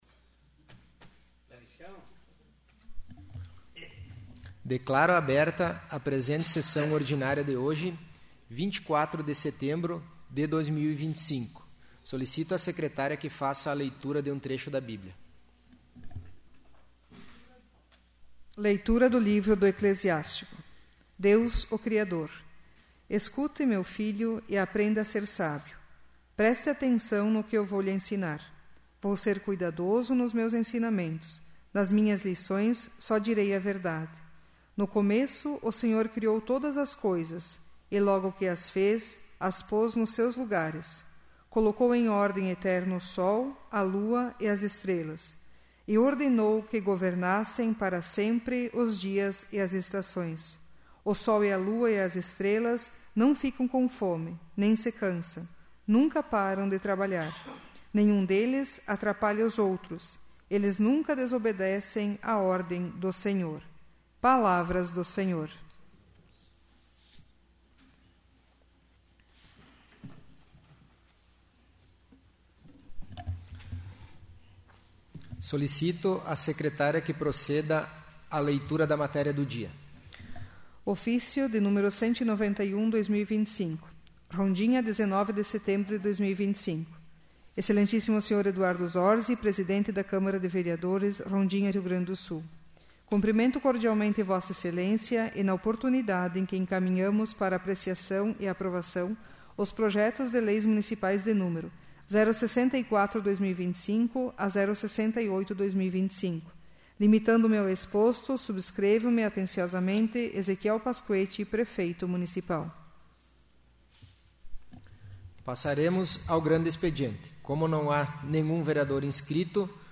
'áudio da sessão do dia 29/04/2026'